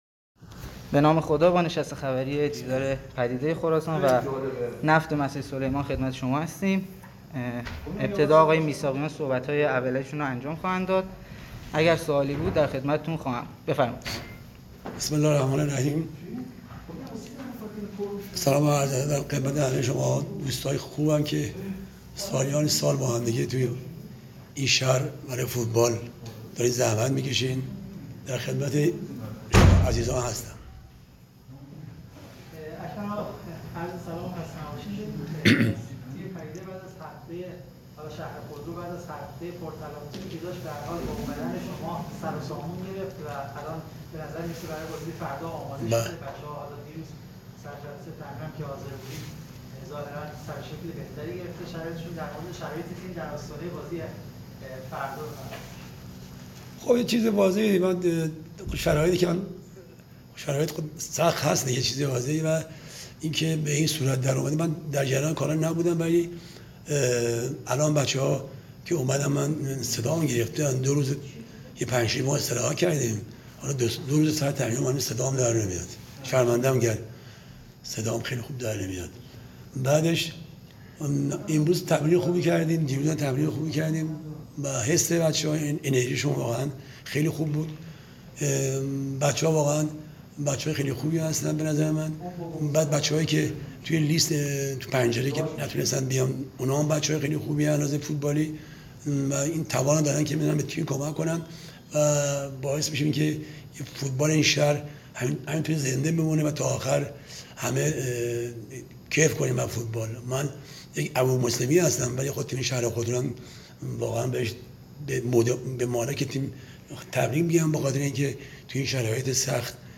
برچسب ها: کنفرانس خبری ، پدیده خراسان ، نفت مسجد سلیمان